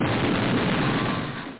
explosion-hi.mp3